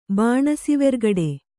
♪ bāṇasivergaḍe